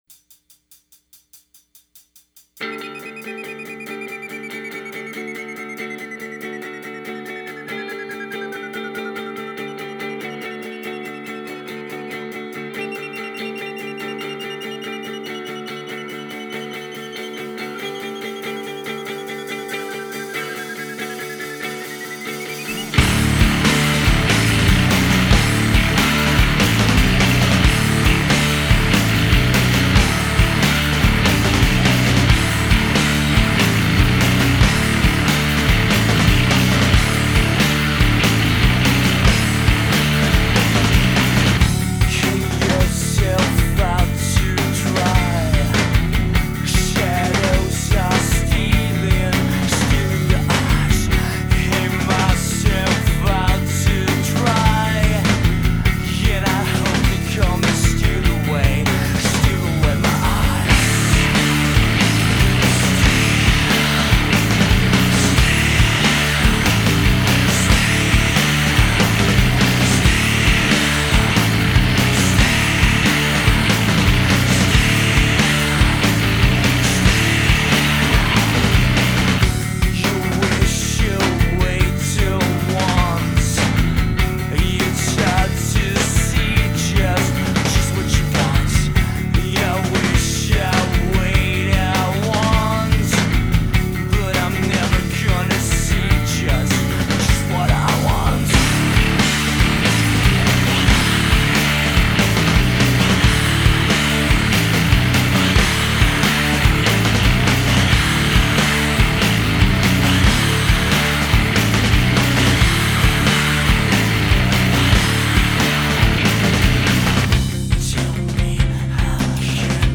All songs recorded at the same session.
Drums
Bass
Guitar
Emo , Indie